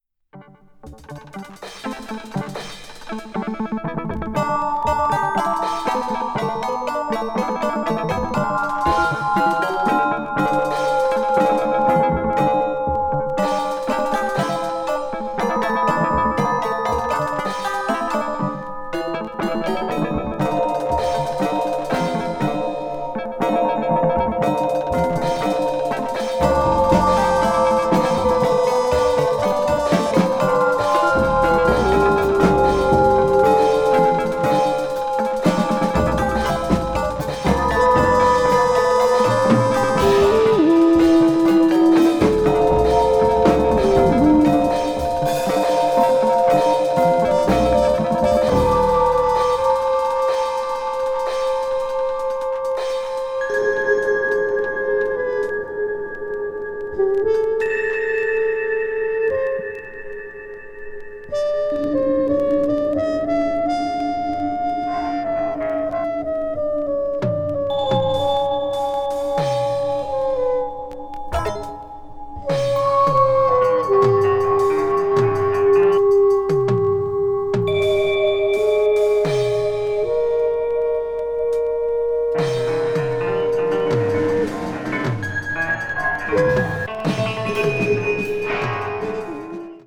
media : EX-/EX-(some light noises. B1 has slightly wear.)